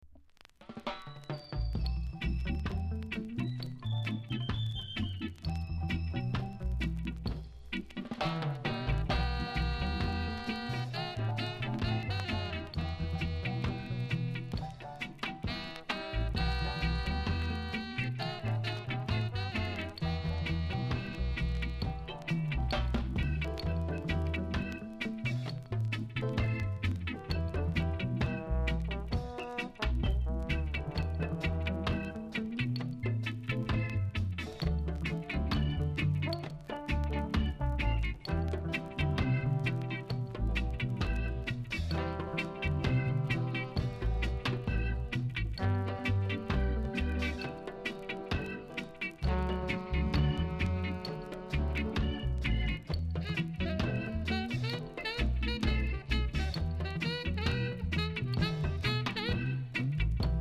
INST!!